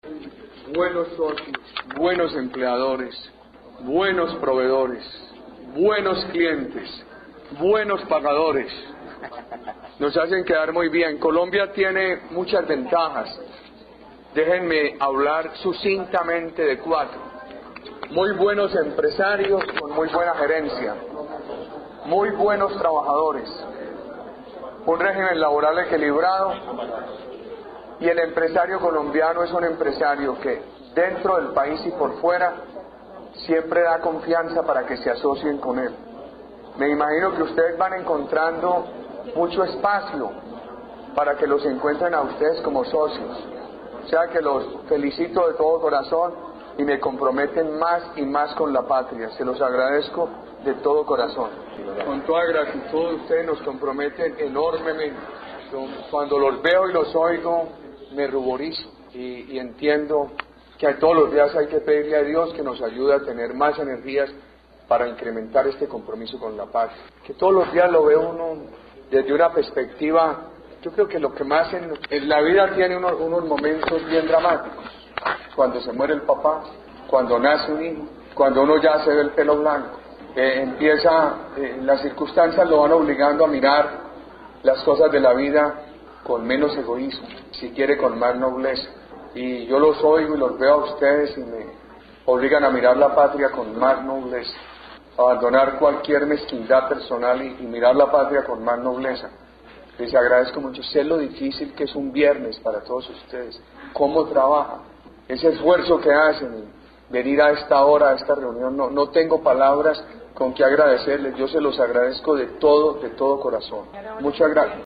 Escuchar Palabras del Presidente Álvaro Uribe ante empresarios colombianos residentes en Washington